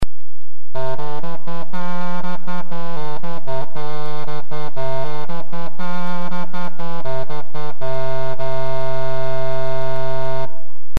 Bass cornamuse - MP3
The bass cornamuse has a wonderfully deep, resonating tone which you can hear on several tracks on my Early Music CD. The two sound clips available for download above illustrate the difference in pitch between the two instruments.